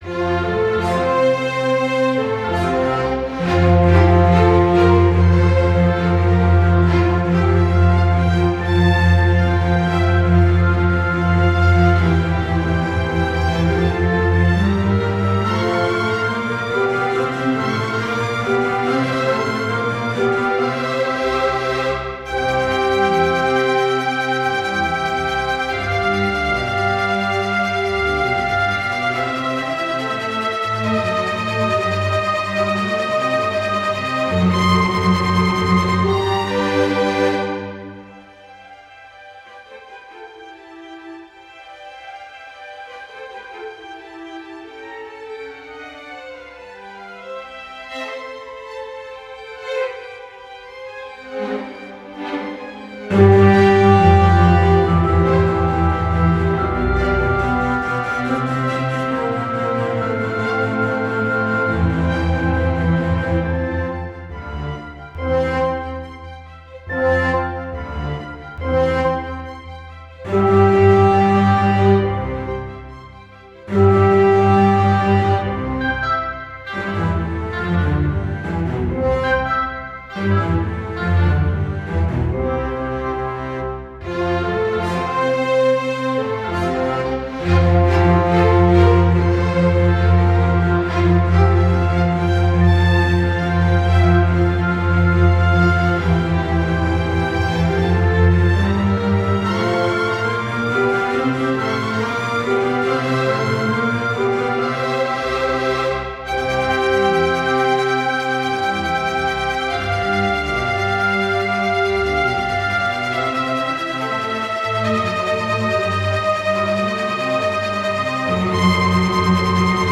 in F Major
Orchestra version
Style: Classical